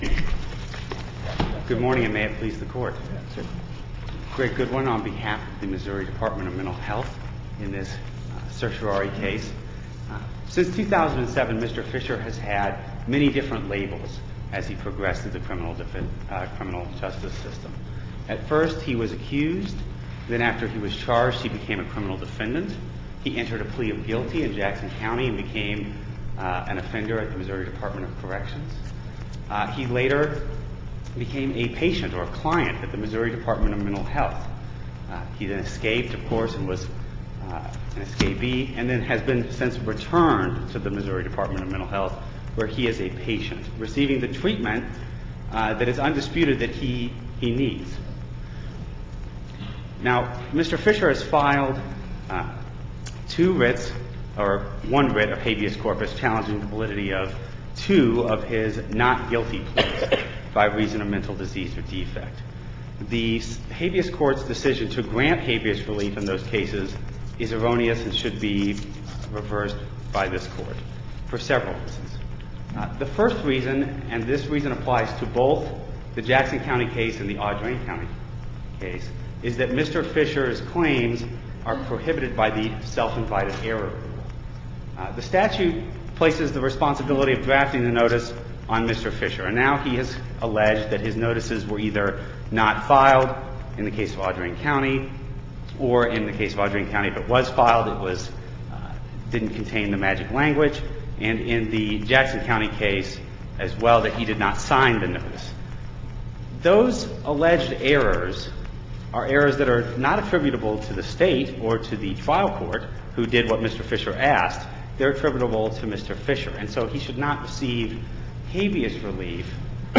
MP3 audio file of arguments in SC95783